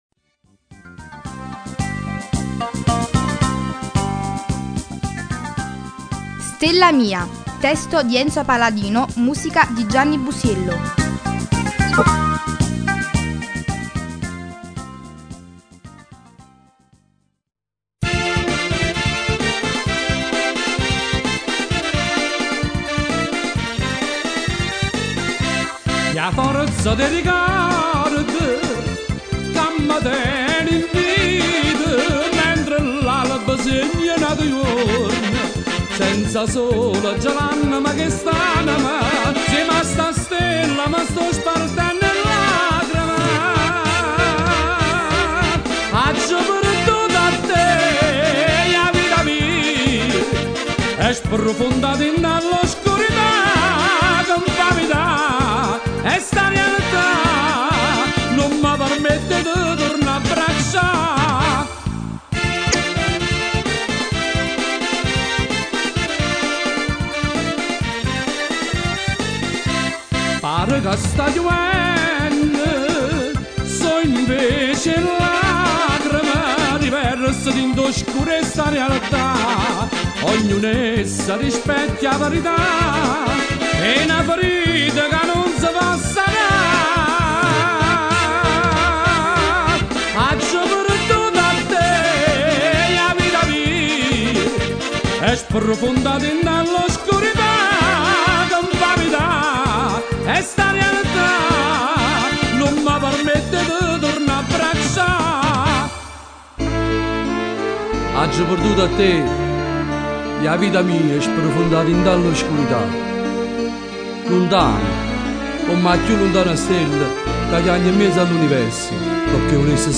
Cantanti